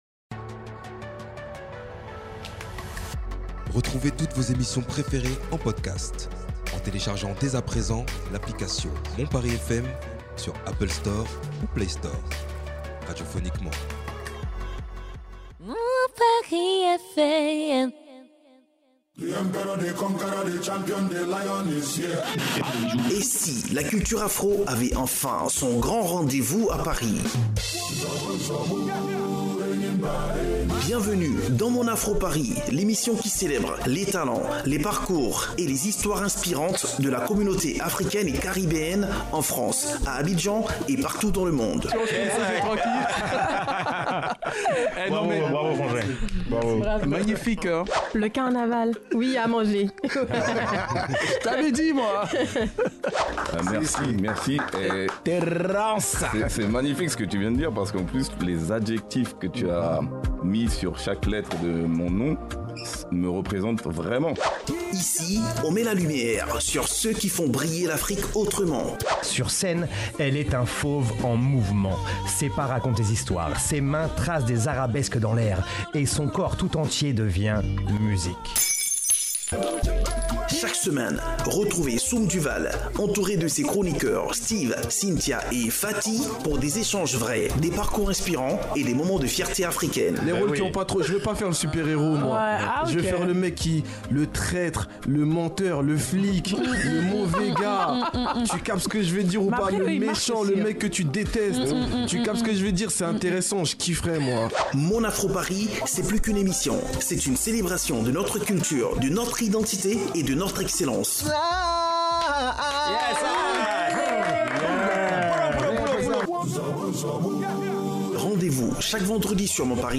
Deux voix.